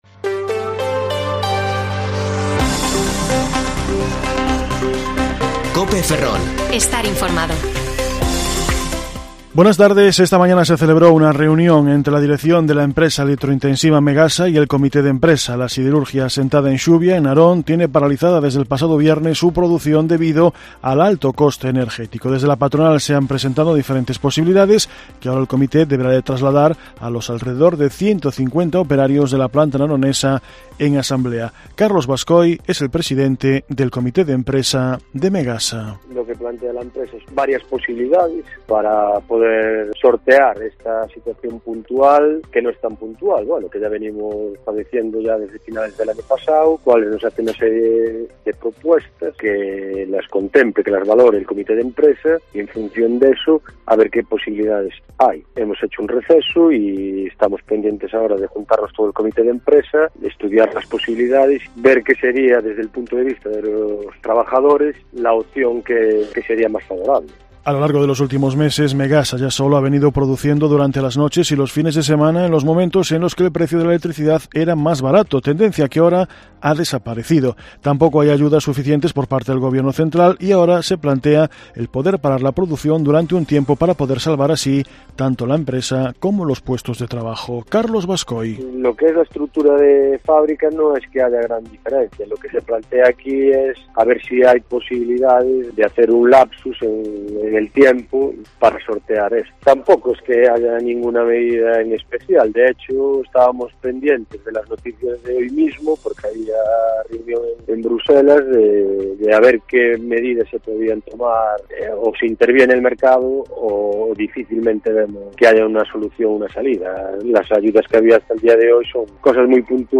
Informativo Mediodía COPE Ferrol 8/3/2022 (De 14,20 a 14,30 horas)